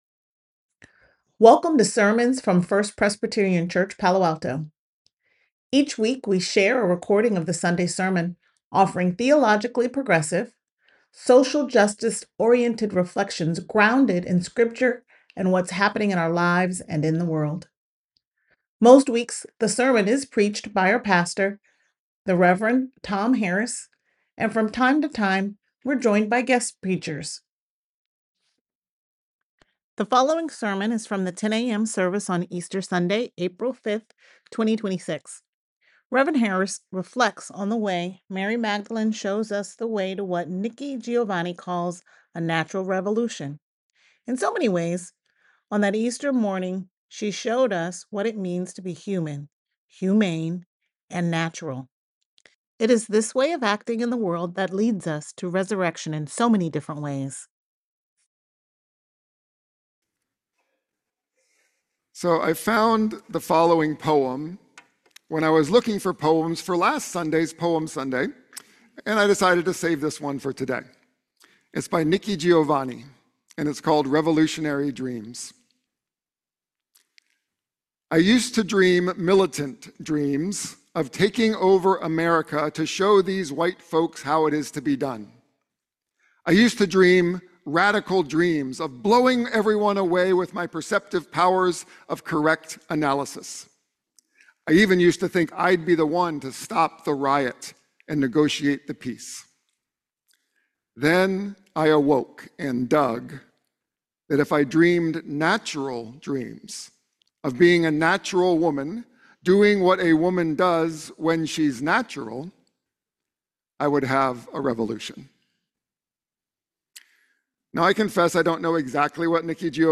The following sermon is from the 10 a.m. service on Easter Sunday, April 5th, 2026.